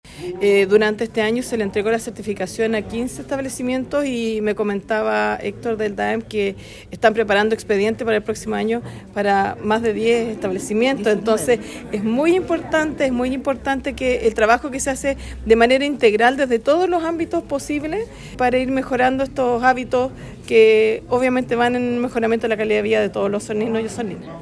La autoridad destacó el trabajo que se está realizando desde el Departamento de Administración de Educación Municipal de Osorno, pues son más de 10 los establecimientos que están buscando obtener esta certificación medioambiental.